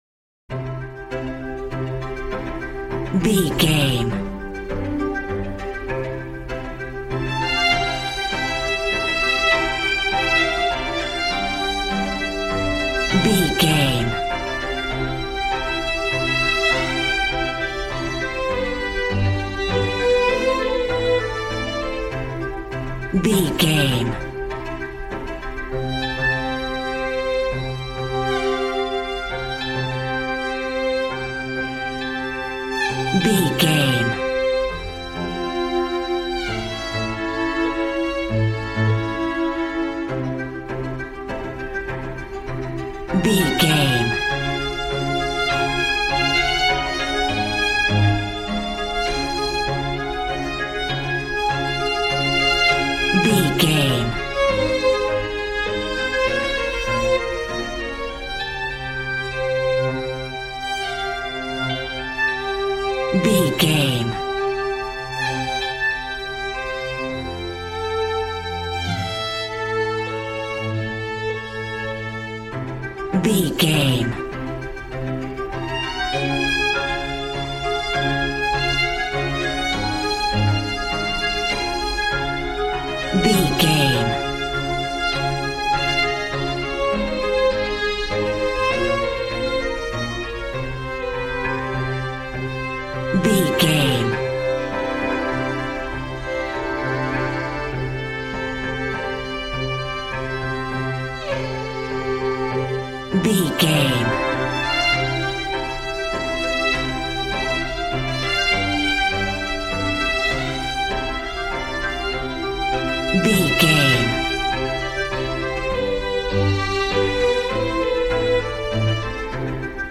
Modern film strings for romantic love themes.
Regal and romantic, a classy piece of classical music.
Ionian/Major
regal
cello
violin
brass